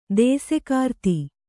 ♪ dēsekārti